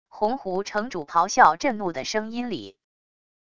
洪湖城主咆哮震怒的声音里wav音频